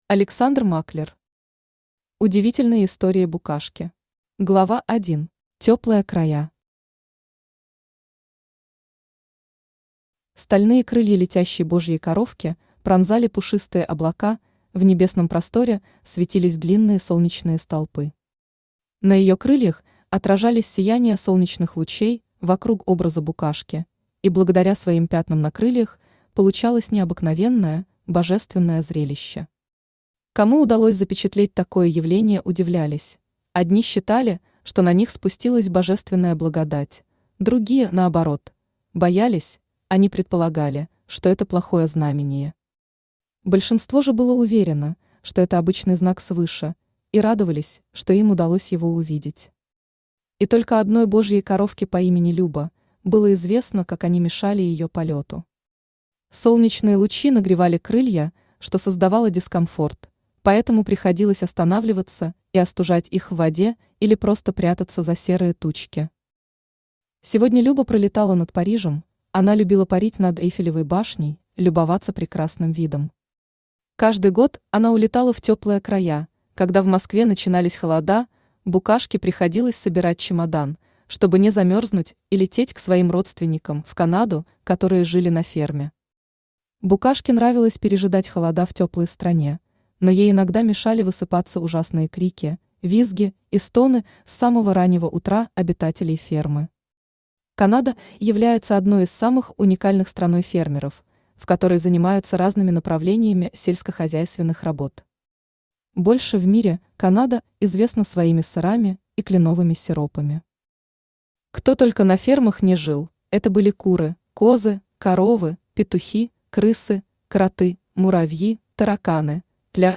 Аудиокнига Удивительные истории букашки | Библиотека аудиокниг
Aудиокнига Удивительные истории букашки Автор Александр Германович Маклер Читает аудиокнигу Авточтец ЛитРес.